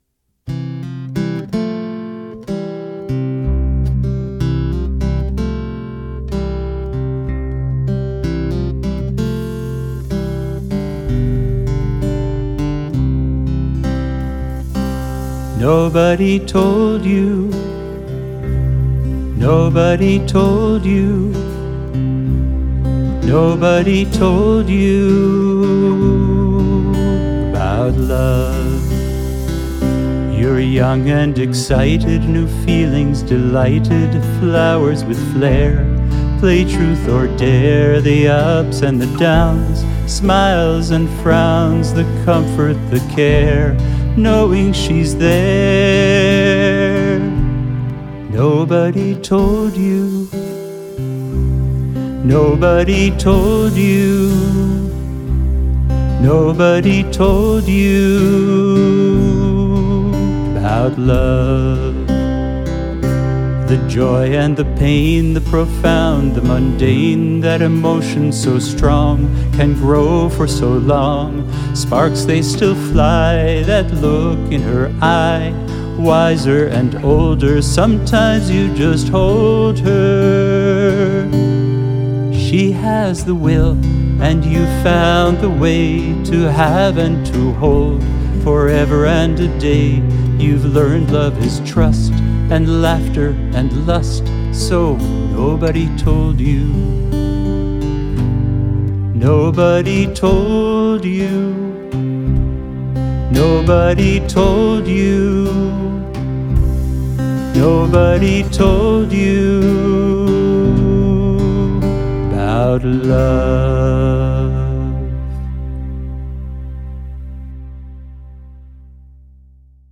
vocals, guitar, kazoo
bass, keyboards, mandolin
drums, djembe, goat hooves, tambourine
saxes